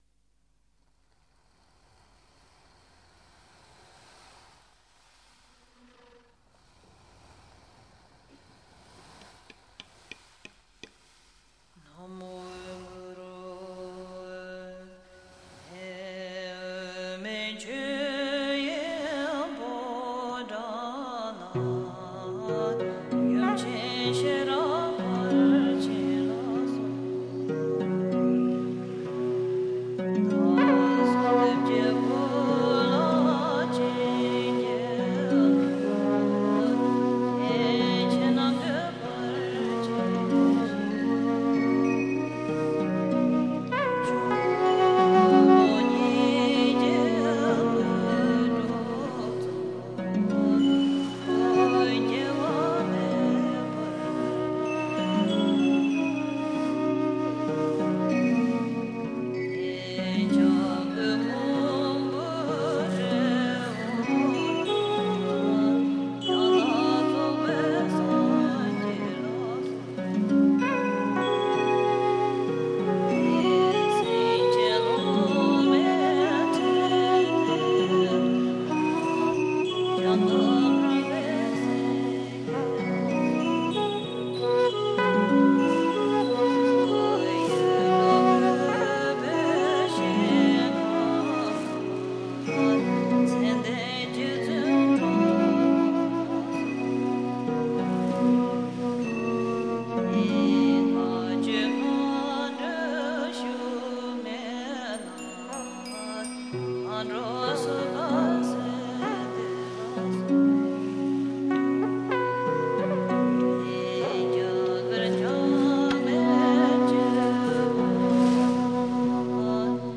live in Carpi
tastiere, samples, chitarra, tin whistle
voce recitante
flauti e sax soprano
percussioni